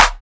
Clap (Love).wav